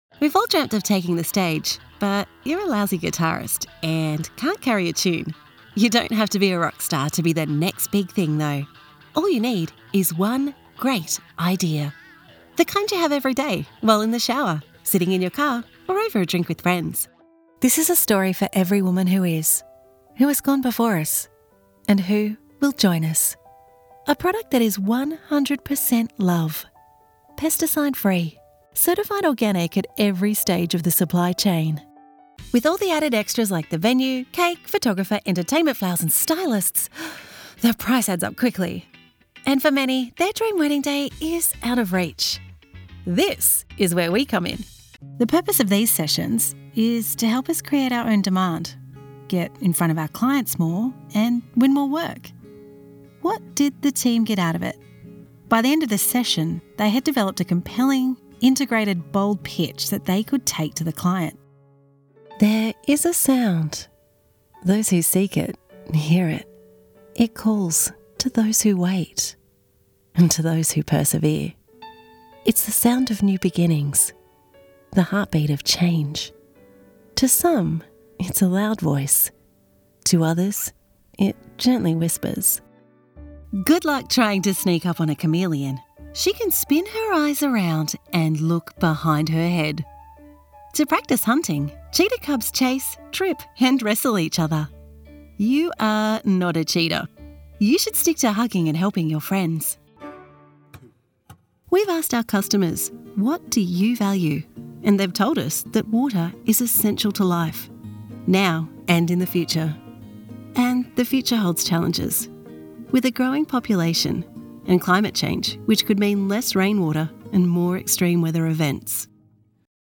conversational / natural style voice overs